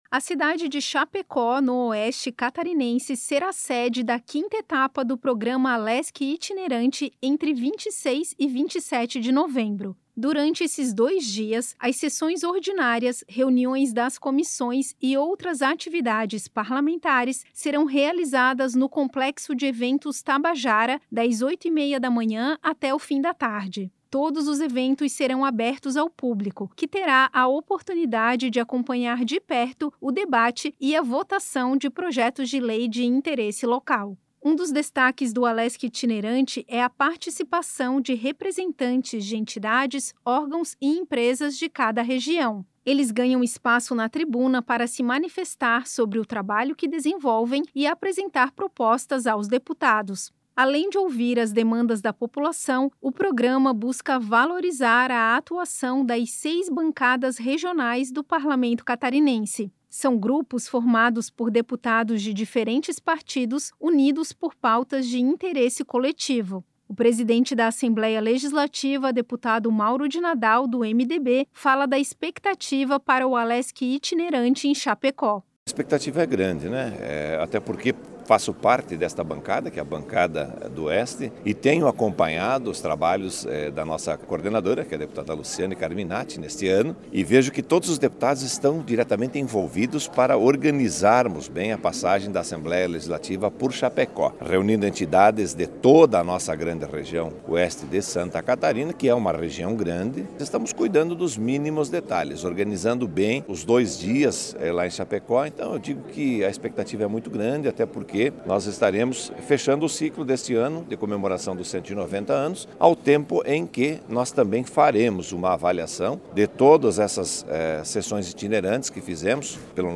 Entrevista com:
- deputado Mauro de Nadal (MDB), presidente da Alesc.